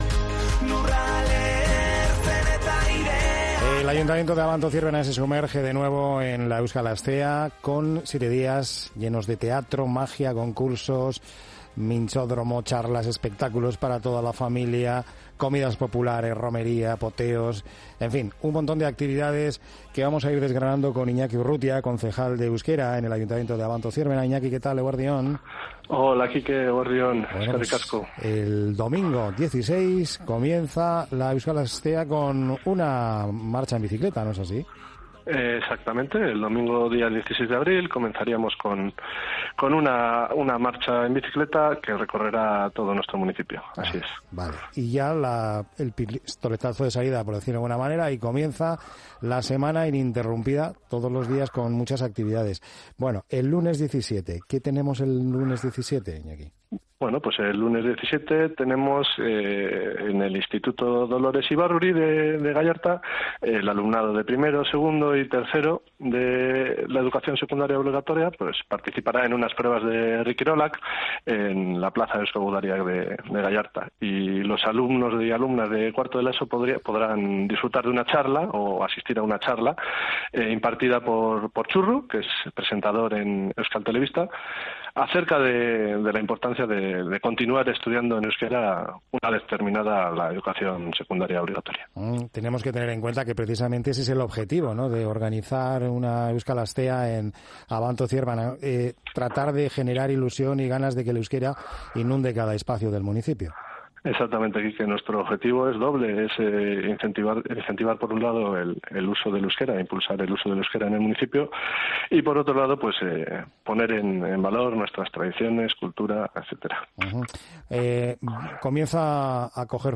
Iñaki Urrutia, Concejal de Euskera en el Ayuntamiento de Abanto-Zierbena, nos ha detallado el programa de actividades de la Euskal Astea que se celebrará entre el 16 y el 23 de abril en la localidad minera